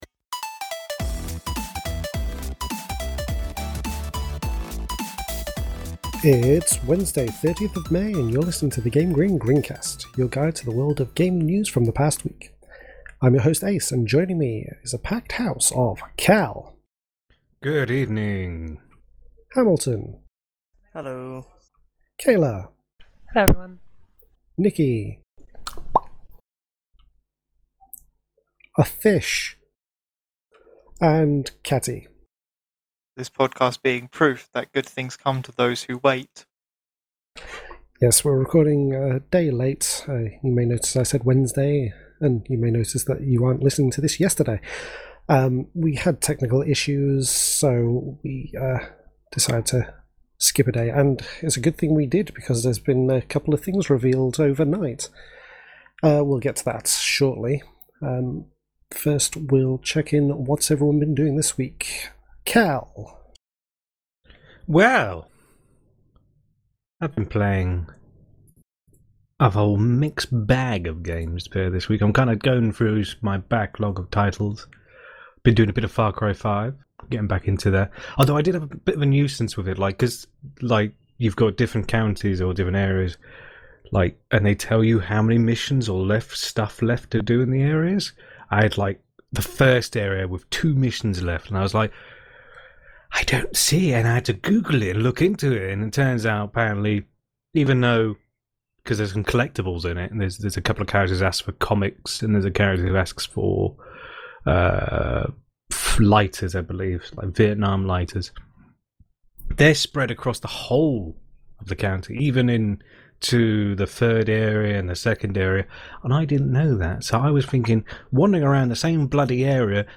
This week on the live GrinCast, we talked about how Steam Link was rejected by Apple, Mirage: Arcane Warfare was Slain by GDPR and The Wolf Among Us 2 has been delayed to 2019.